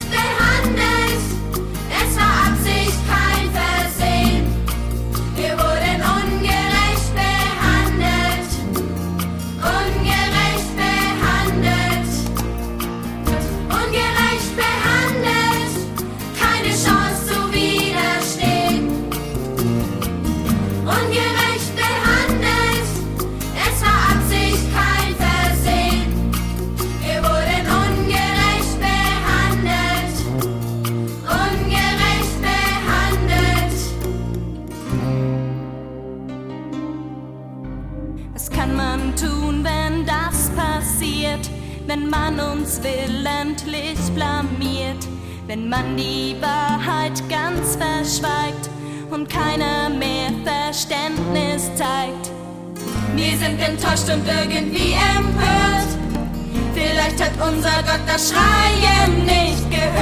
Ein Musical für die ganze Familie
Mit fetzigen Liedern und modernen Arrangements.
Kinderlieder